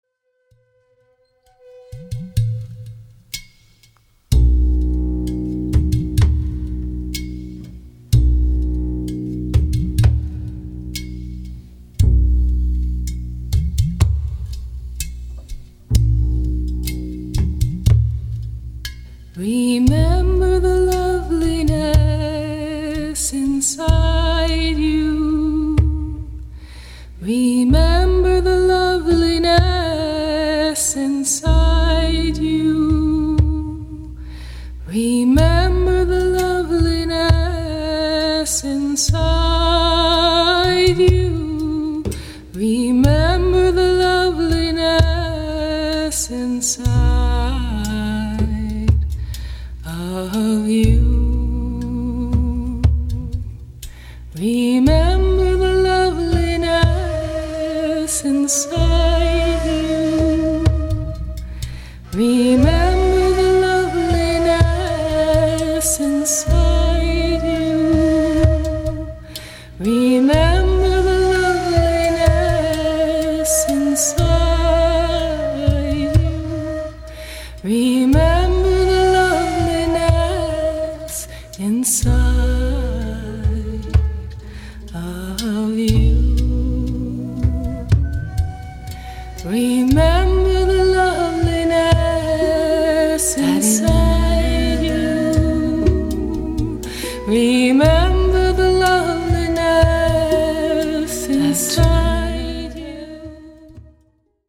Genre: World Fusion.
gentle, meditative strains